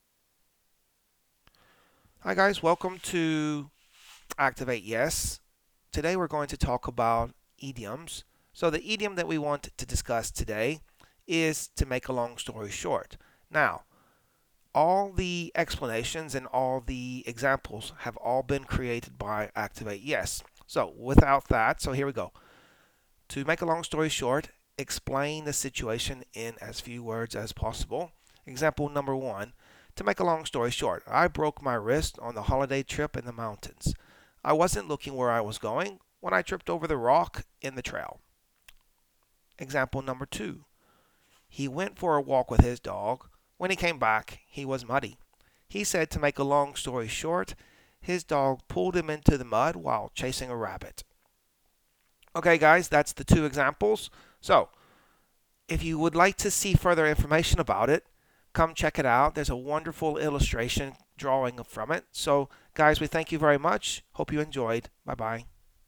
Enjoy our first audio idiom explanation.